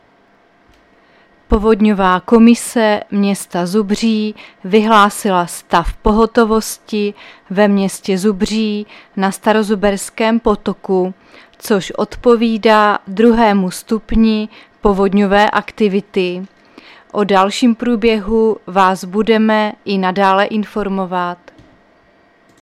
Záznam hlášení místního rozhlasu 14.9.2024